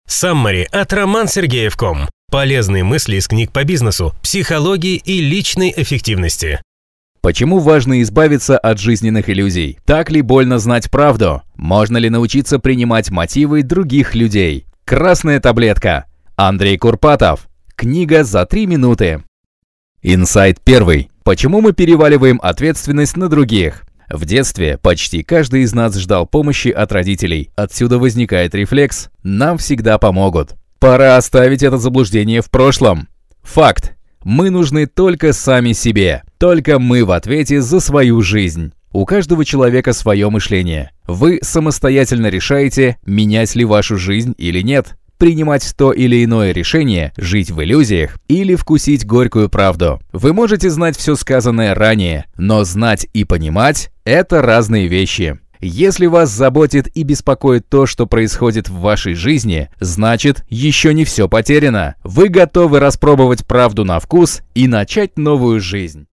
Аудиокнига Саммари на книгу «Красная таблетка».